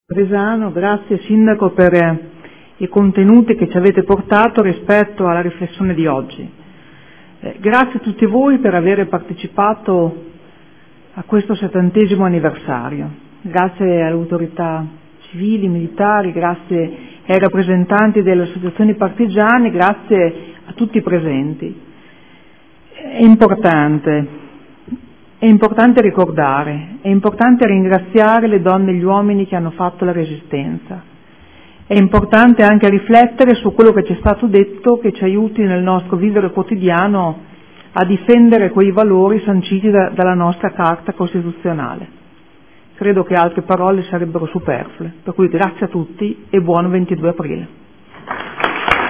Intervento conclusivo del Presidente del Consiglio Comunale, in ricordo del 70° anniversario della Liberazione